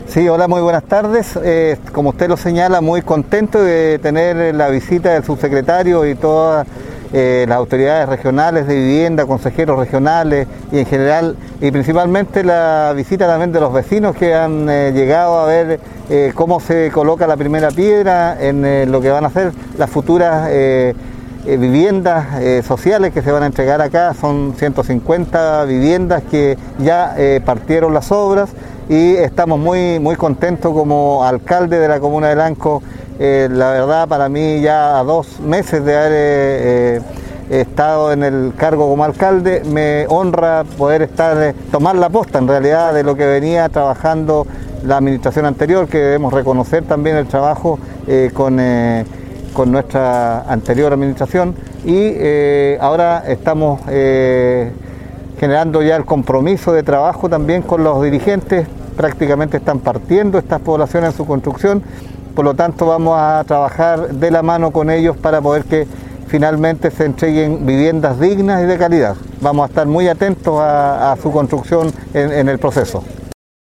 Con la asistencia de autoridades regionales y nacionales, este miércoles se realizó la colocación de la primera piedra, para la construcción del proyecto habitacional Siete Lagos en la comuna de Lanco, el cual albergará a 150 familias del territorio.